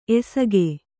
♪ esagu